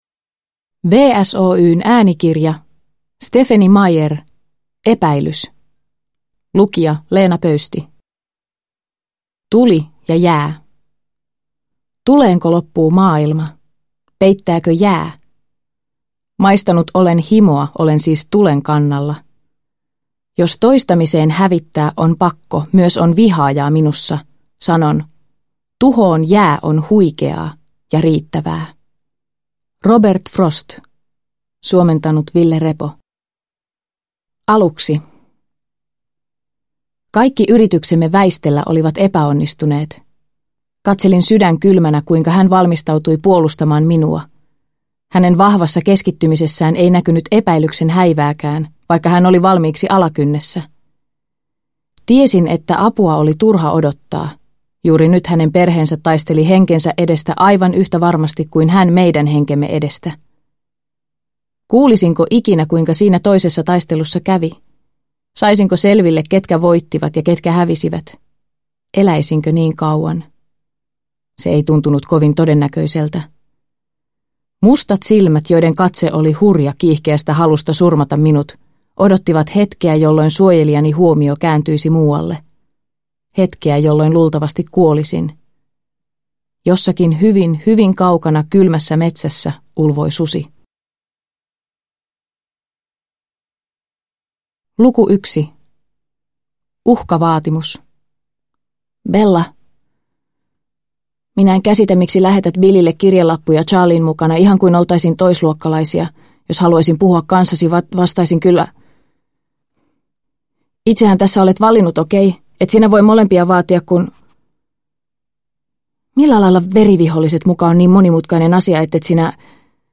Epäilys – Ljudbok – Laddas ner